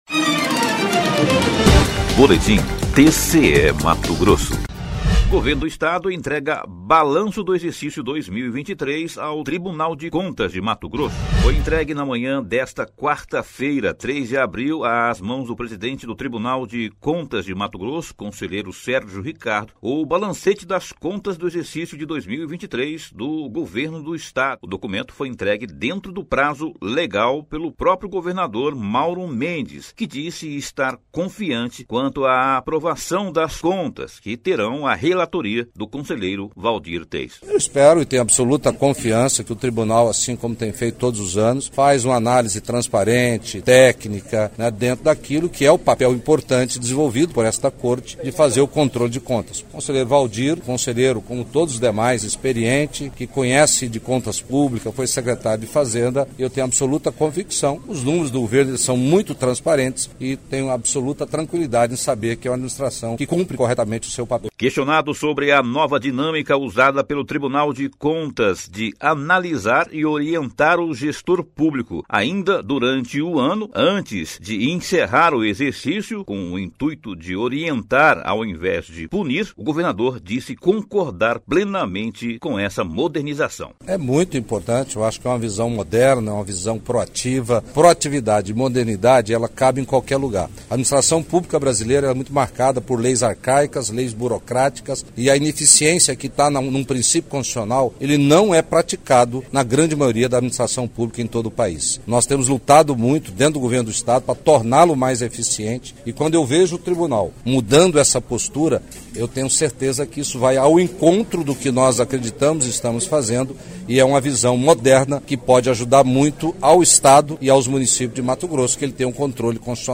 Sonora: Mauro Mendes – governador de Mato Grosso
Sonora: Sérgio Ricardo – conselheiro presidente do TCE-MT